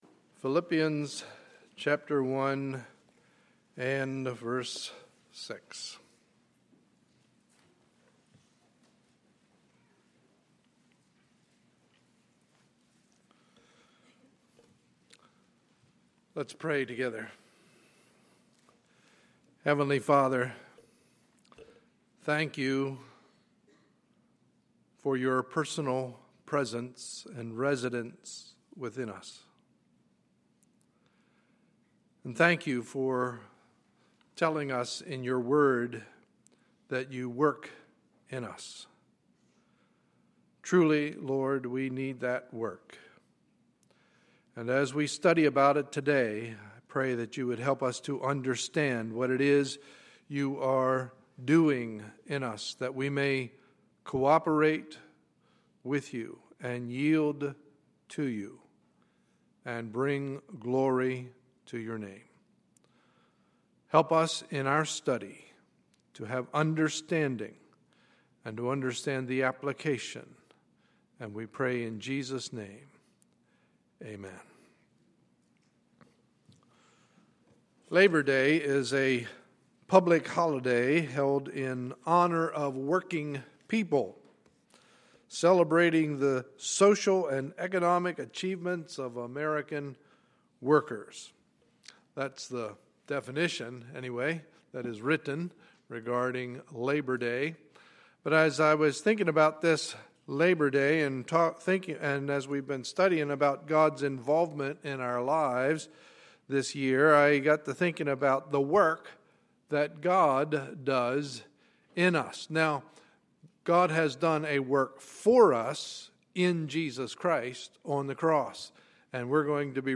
Sunday, September 1, 2013 – Morning Service